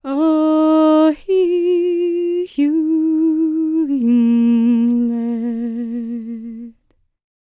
L  MOURN C06.wav